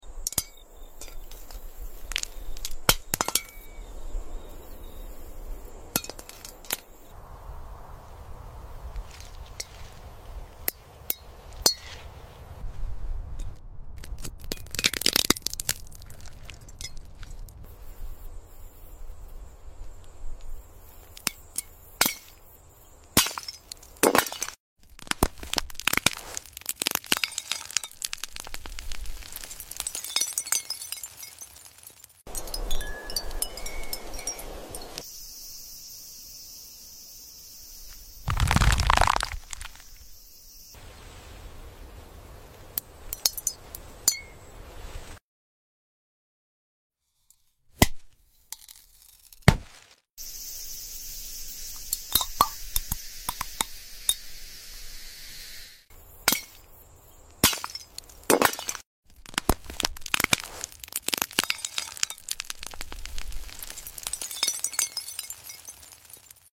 imagine About Glass Fruits Asmr sound effects free download